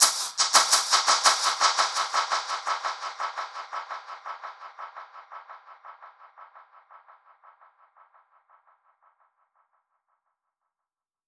Index of /musicradar/dub-percussion-samples/85bpm
DPFX_PercHit_B_85-07.wav